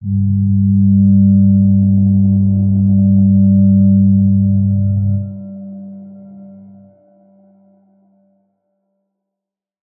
G_Crystal-G3-f.wav